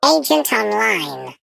Sfx_tool_spypenguin_vo_enter_05.ogg